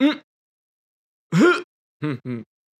casting_success.wav